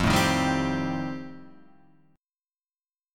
E#79 chord